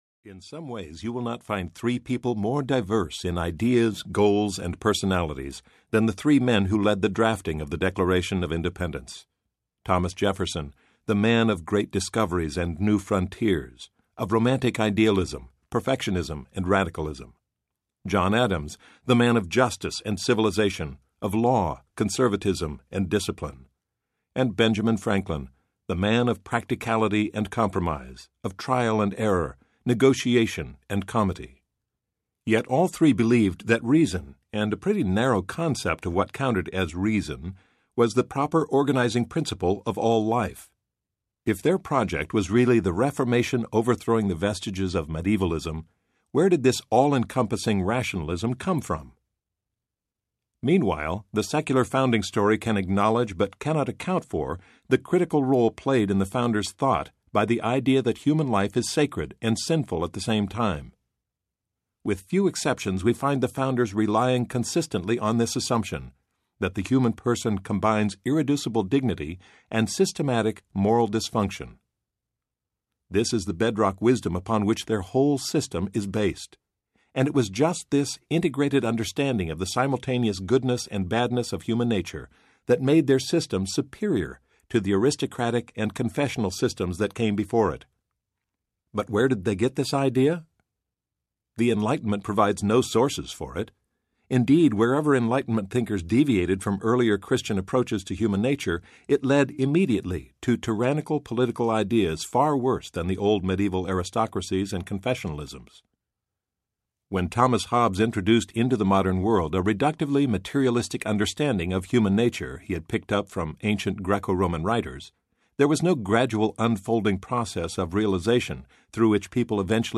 Joy for the World Audiobook
10.8 – Unabridged